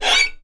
Menu Grow Taller Sound Effect